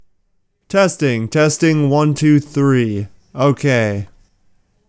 Hmm I think the rolloff does get rid of some of the ambient background rumble, so that’s good.